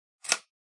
描述：随机的毛刺声
标签： 扑通 的Dubstep 毛刺 声音
声道立体声